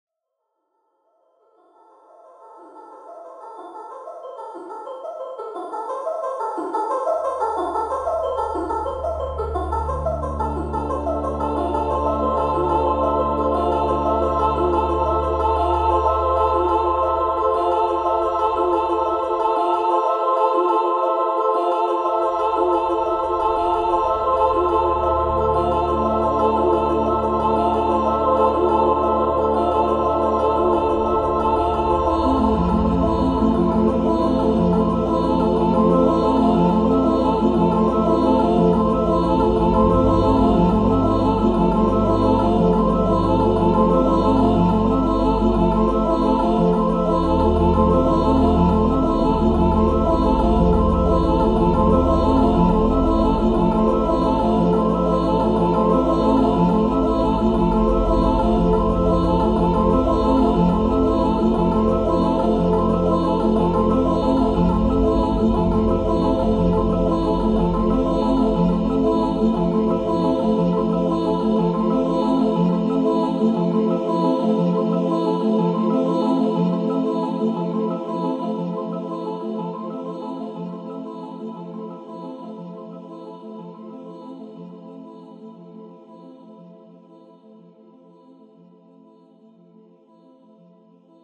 A/V Night